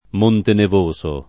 [ m 1 nte nev 1S o ]